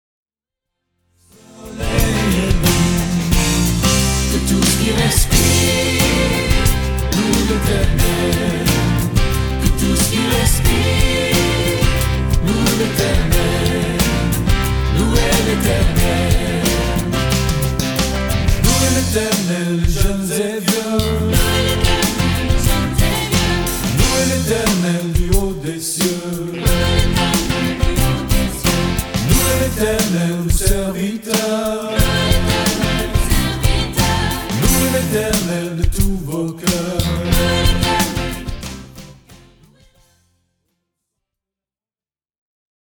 Chants de louange originaux interprétés par leurs auteurs